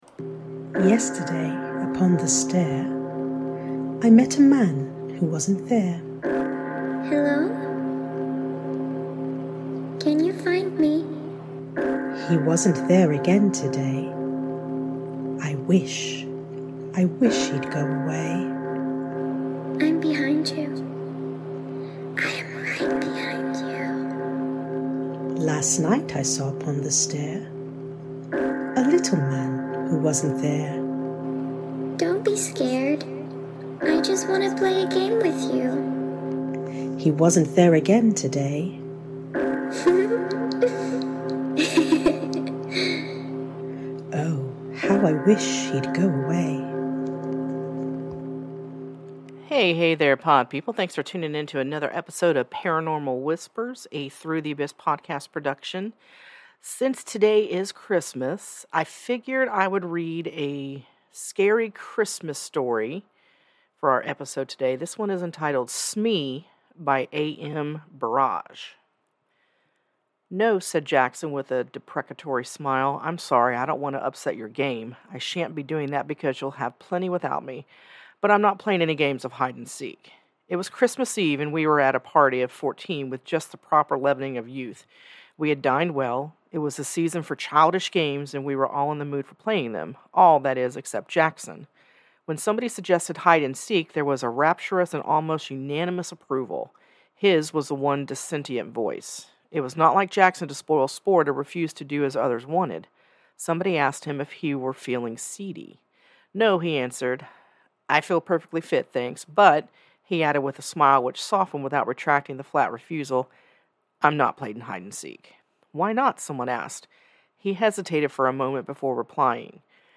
SInce it's Christmas, I thought I would read a scary Christmas ghost story that i happened upon and really enjoyed.